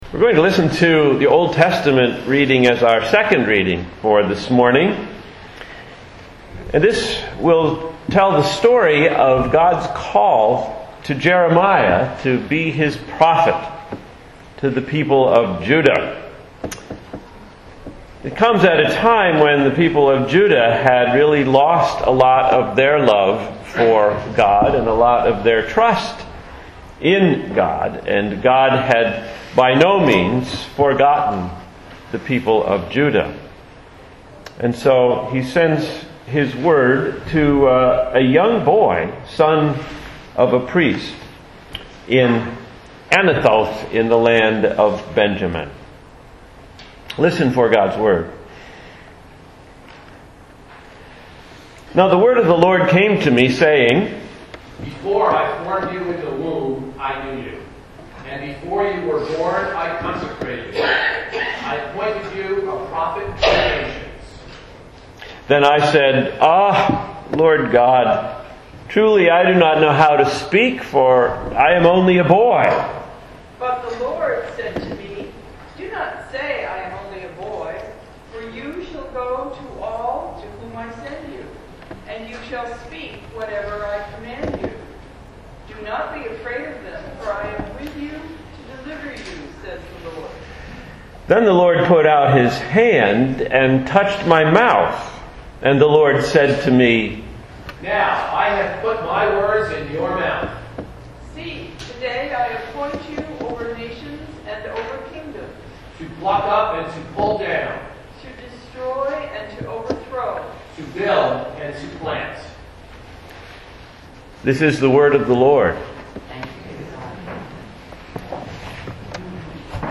Sermon for 1/30/2010 – Jeremiah the Prophet